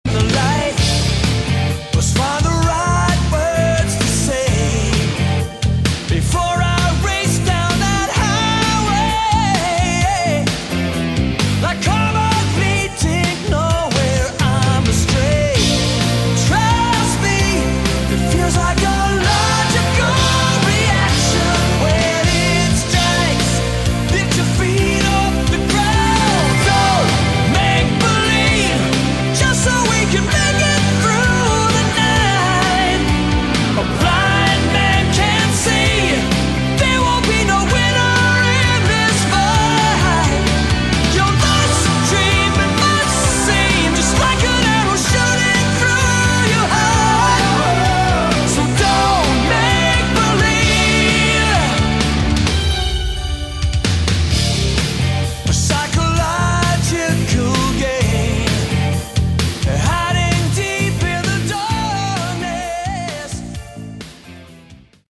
Category: AOR
guitars
lead and backing vocals
bass
drums
keyboards